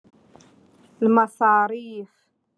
Moroccan Dialect- Rotation Three- Lesson Five